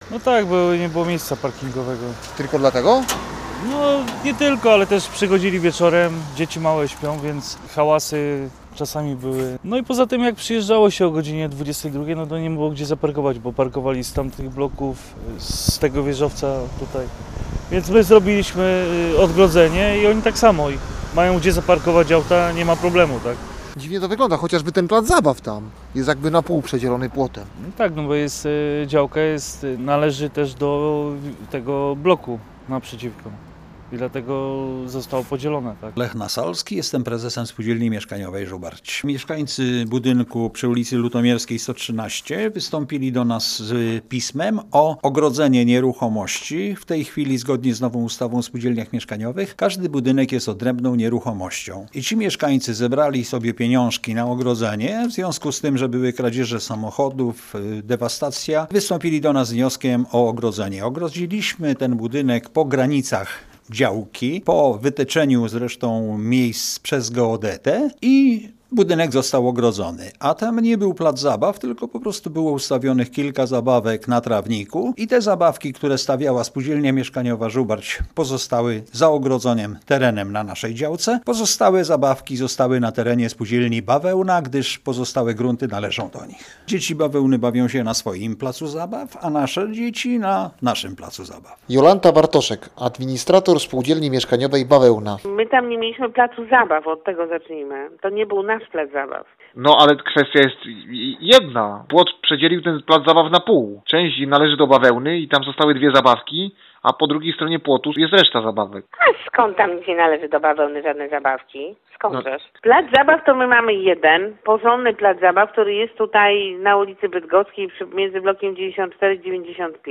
Posłuchaj relacji naszego reportera i dowiedz się więcej: Nazwa Plik Autor Ogrodzenie przedzielające plac zabaw audio (m4a) audio (oga) ZDJĘCIA, NAGRANIA WIDEO, WIĘCEJ INFORMACJI Z ŁODZI I REGIONU ZNAJDZIESZ W DZIALE “WIADOMOŚCI”.